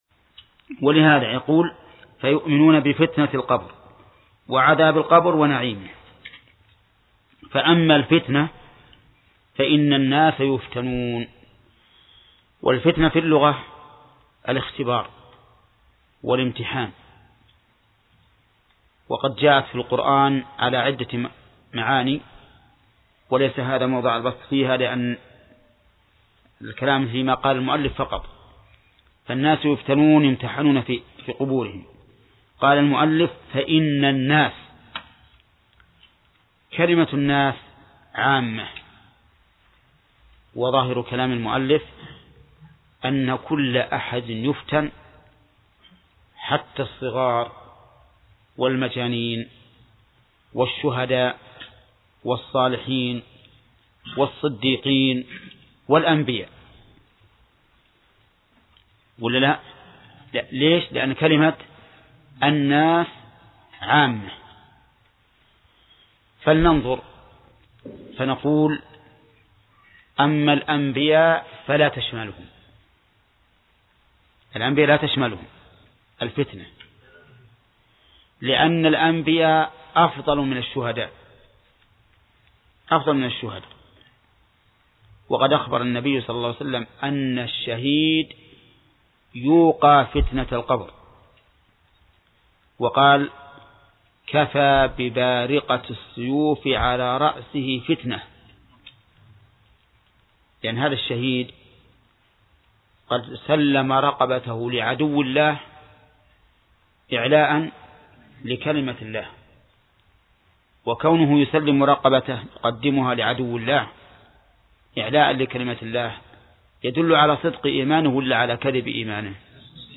درس : 28 : من صفحة: (109)، (قوله: فأما الفتنة ....)، إلى صفحة: (139)، (قوله: ((تنصب الموازين)) ....).